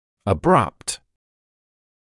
[ə’brʌpt][э’брапт]внезапный, неожиданный; резкий